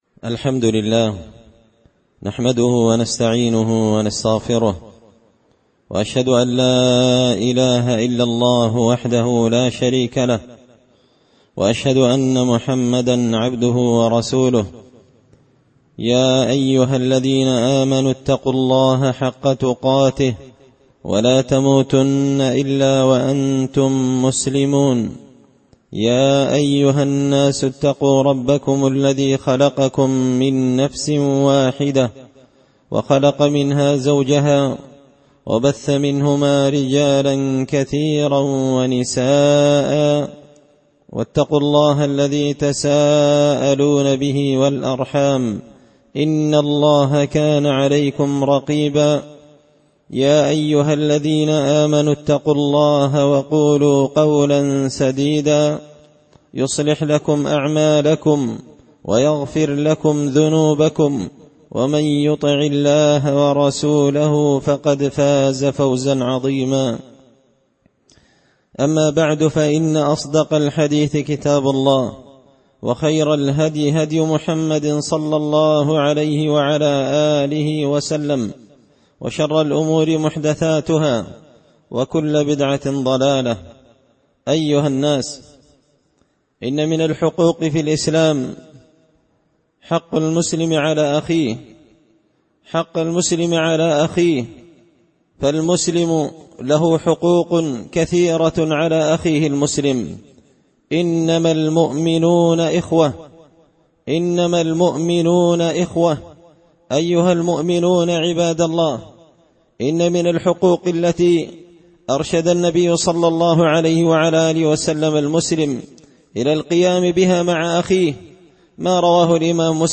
خطبة جمعة بعنوان – حق المسلم على المسلم
دار الحديث بمسجد الفرقان ـ قشن ـ المهرة ـ اليمن